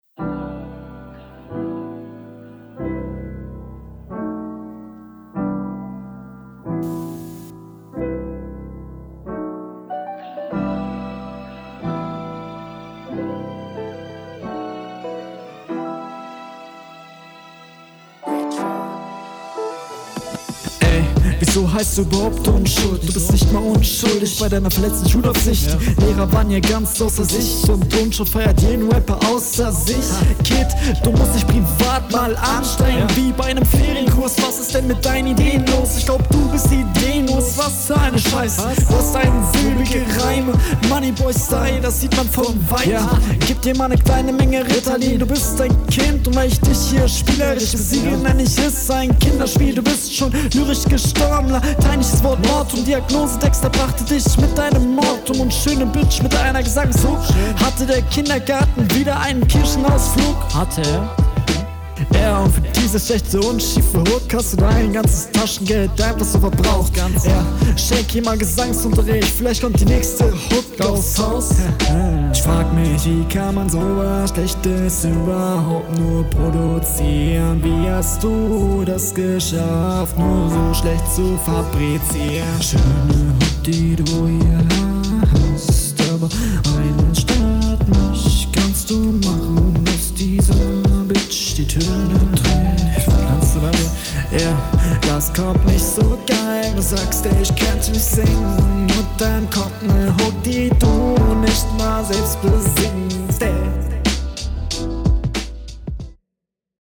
Wieder ein sehr cooler und vor allem melodischer Beat.
Hast jedenfalls mehr Power in der Stimme hier.